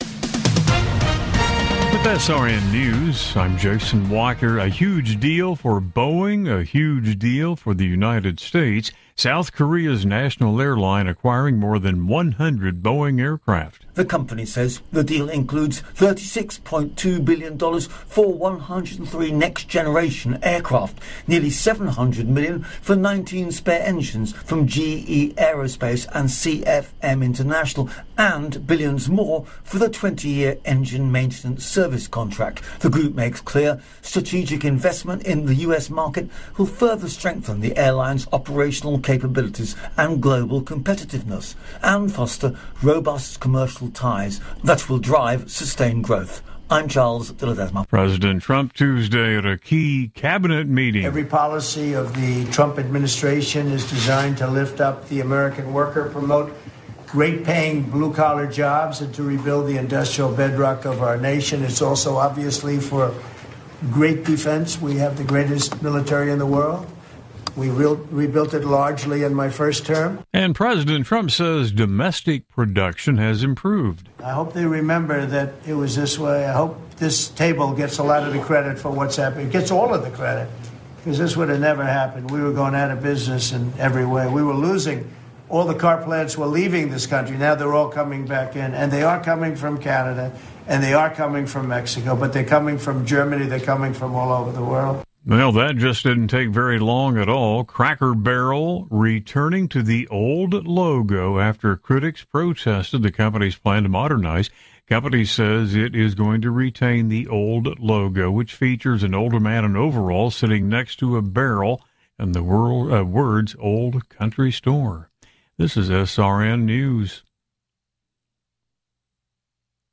Latest news stories from around the world brought to you at the top of the hour